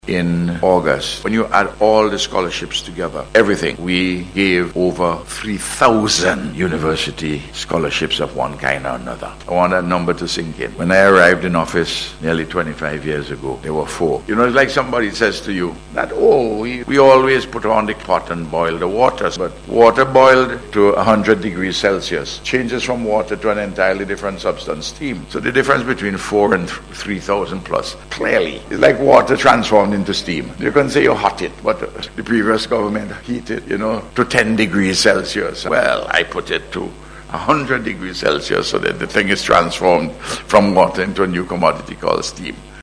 Prime Minister Dr. Ralph Gonsalves made this disclosure during a ceremony held last evening for the presentation of seventy-five Munroe University Scholarships to Vincentian students at the Beachcombers Hotel Conference Room.